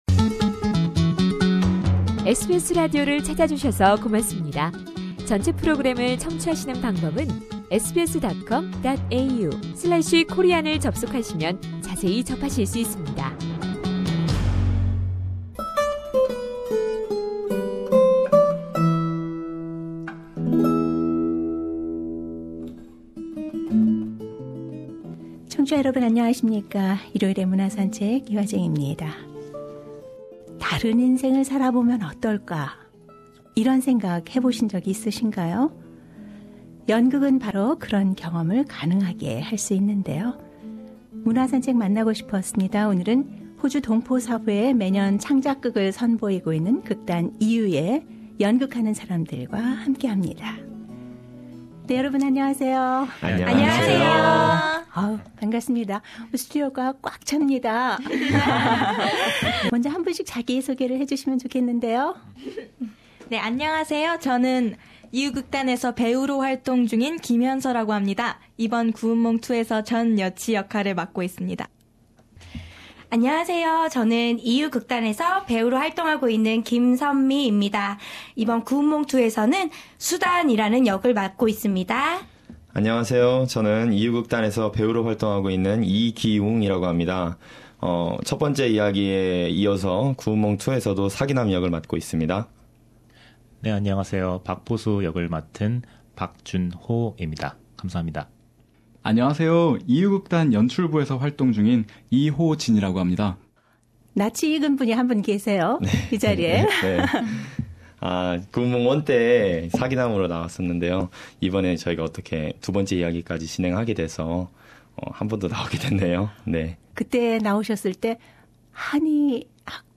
SBS Radio features an interview with its key players.